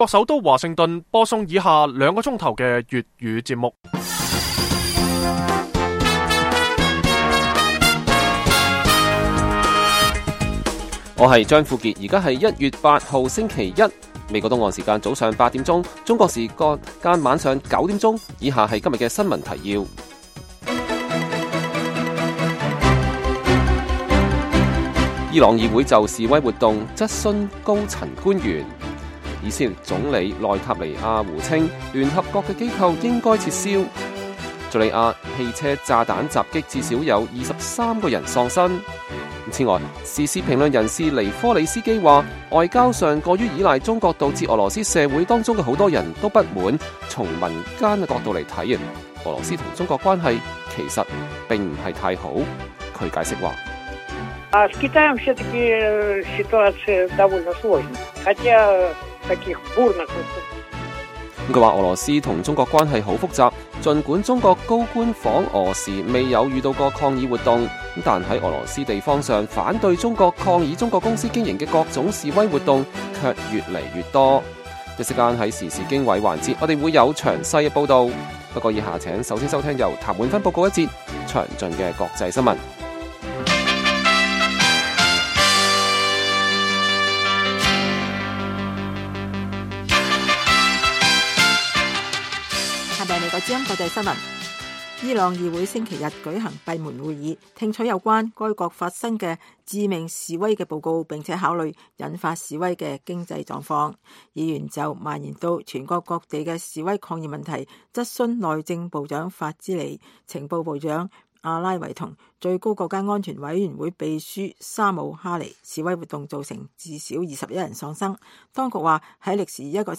粵語新聞 晚上9-10點
北京時間每晚9－10點 (1300-1400 UTC)粵語廣播節目。內容包括國際新聞、時事經緯和英語教學。